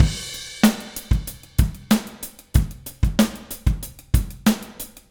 Trem Trance Drums 01 Crash.wav